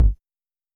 S 78_BDrum.wav